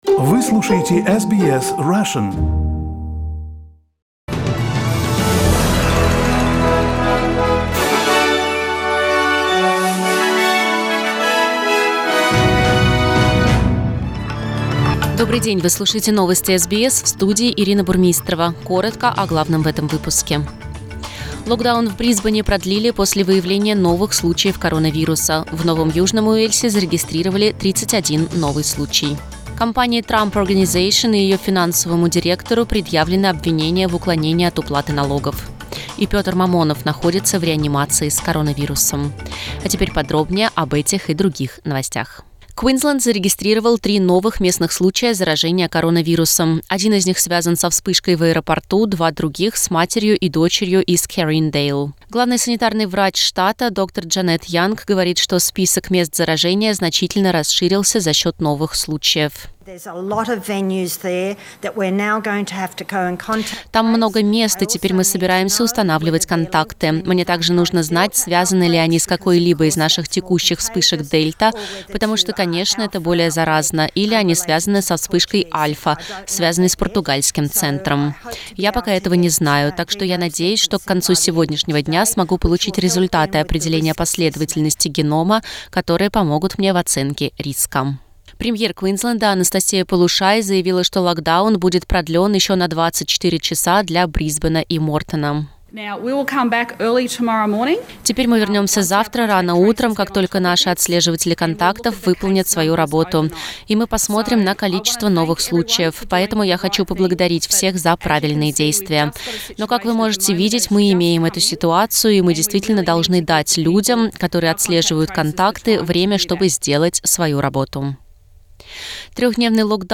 Новости SBS на русском языке - 2.07